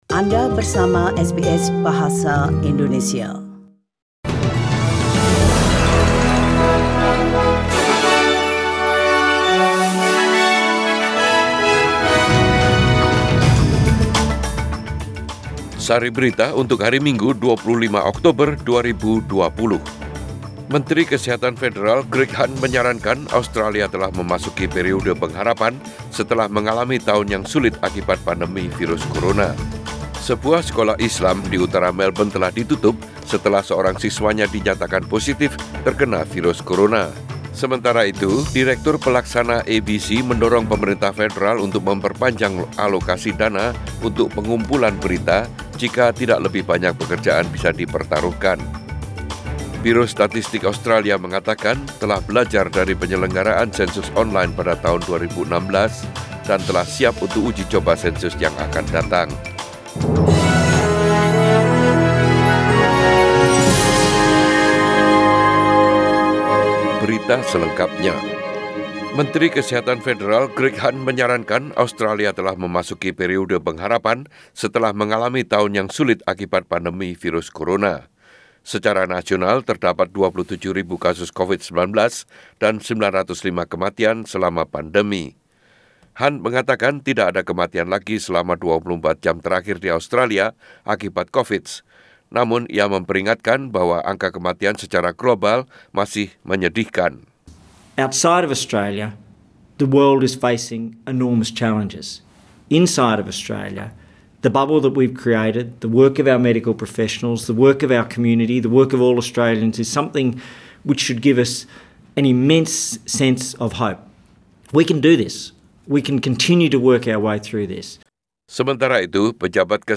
Warta Berita SBS Radio Program Bahasa Indonesia - 25 Oktober 2020